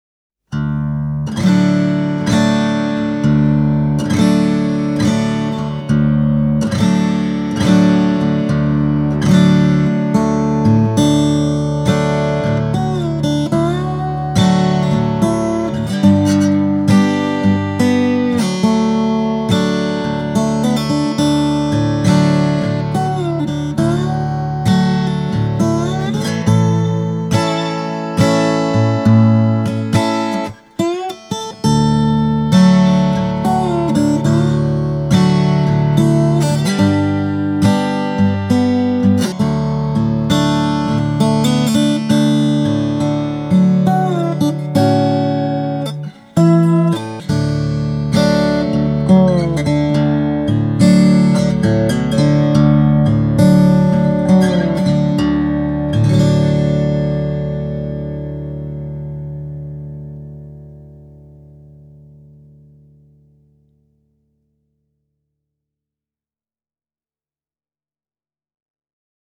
Bediaz Black Gloss -kitaralla on hyvin raikas ääni pienellä keskialueen korostuksella. Matalakoppaiseksi akustiseksi tällä Weissenborn-kopiolla on yllättävän iso soundi.
Äänitin kaksi soundiesimerkkiä pelkästään Bediaz-kitaralla: